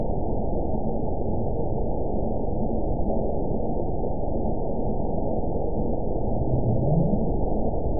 event 922421 date 12/31/24 time 21:18:58 GMT (4 months ago) score 9.15 location TSS-AB02 detected by nrw target species NRW annotations +NRW Spectrogram: Frequency (kHz) vs. Time (s) audio not available .wav